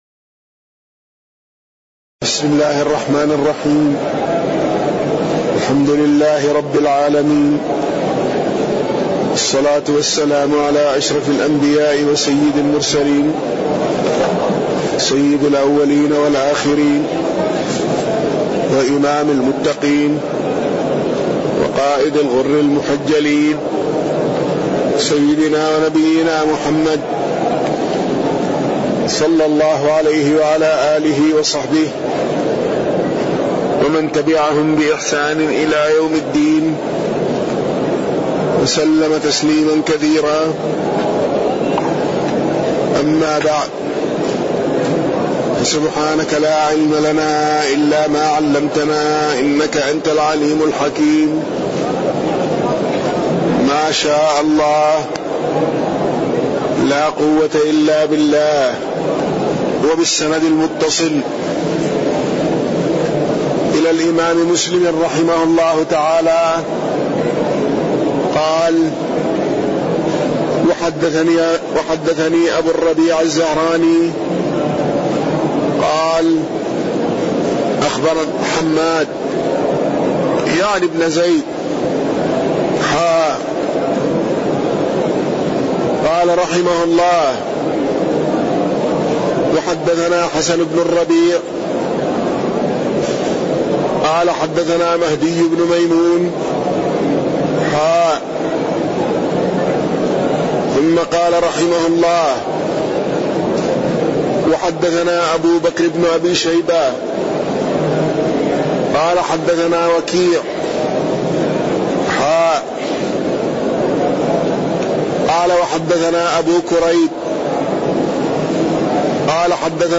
تاريخ النشر ٥ رمضان ١٤٣٠ هـ المكان: المسجد النبوي الشيخ